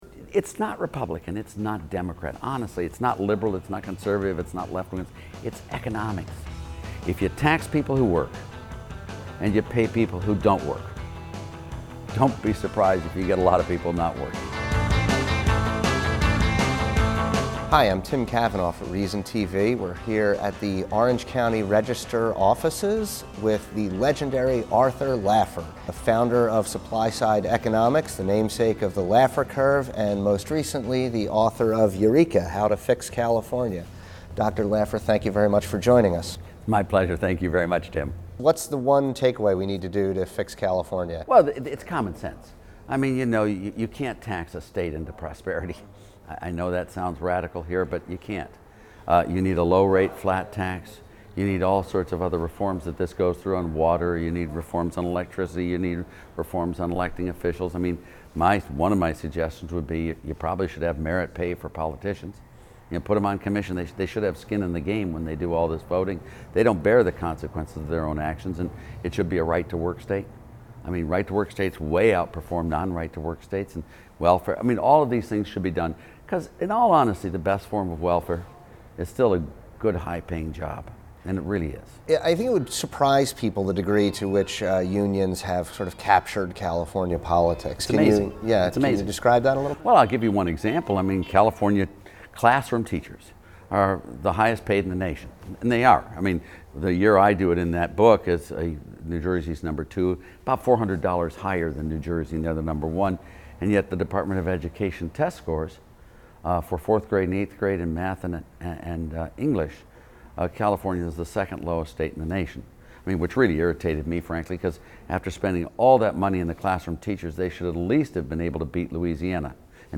at the offices of the Orange County Register